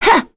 Lewsia_B-Vox_Attack3.wav